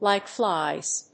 アクセントlike flíes